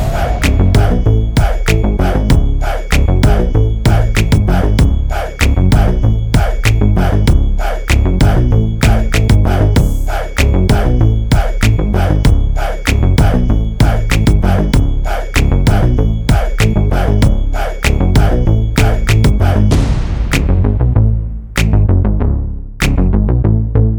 for duet R'n'B / Hip Hop 4:12 Buy £1.50